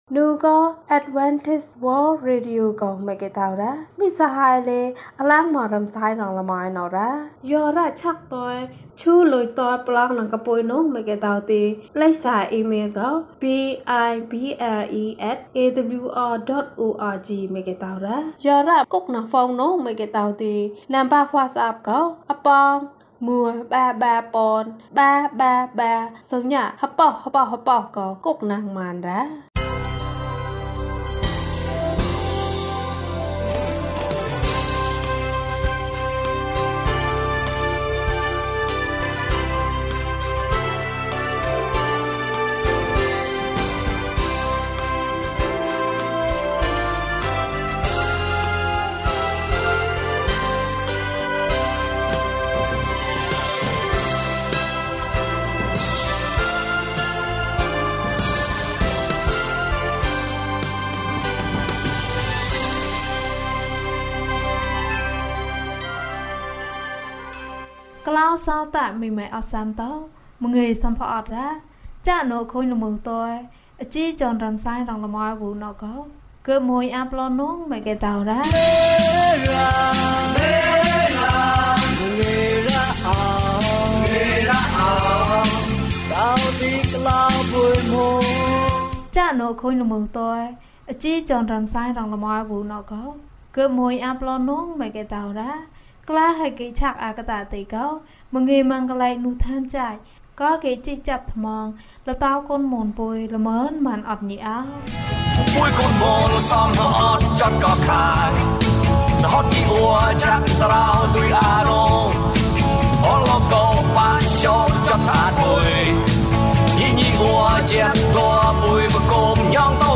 အခြားဘုရားသခင်စကားများကို မျှဝေပါ။ ၀၂ ကျန်းမာခြင်းအကြောင်းအရာ။ ဓမ္မသီချင်း။ တရားဒေသနာ။